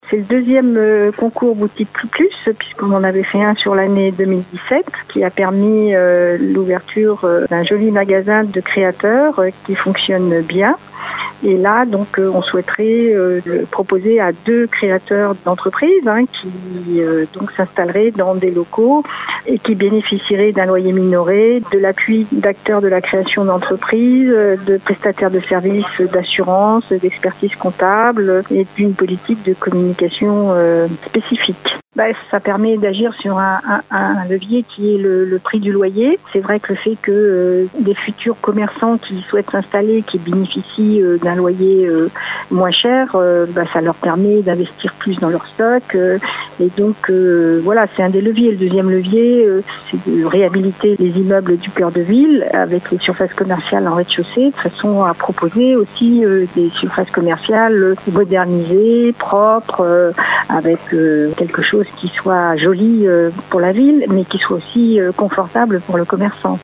Françoise Mesnard, la maire de la ville :